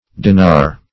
Dinar \Di"nar\ (d[imac]"n[~e]r or d[-e]*n[aum]r"), n. [Ar.